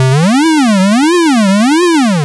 retro_synth_wobble_02.wav